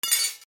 金属 置く
/ M｜他分類 / L01 ｜小道具 / 金属